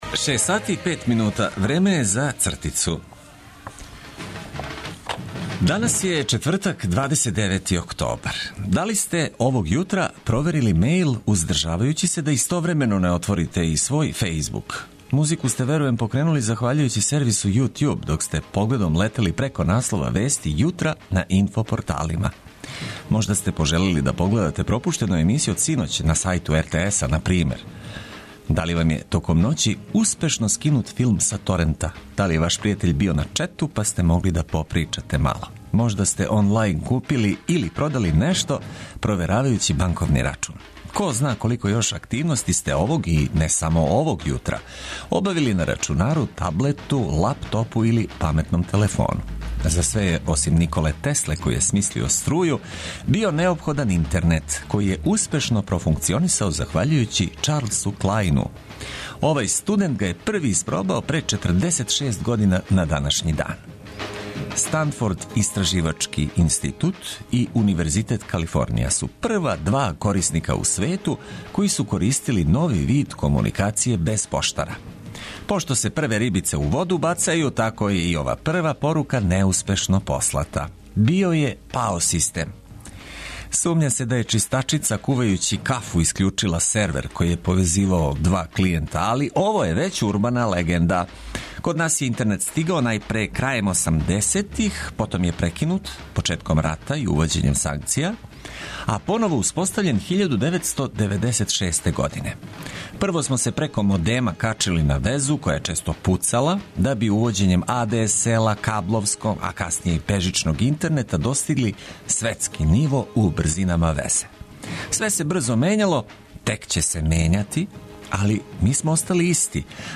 Корисне информације огрнуте добром музиком - то је наш рецепт за лепши почетак дана.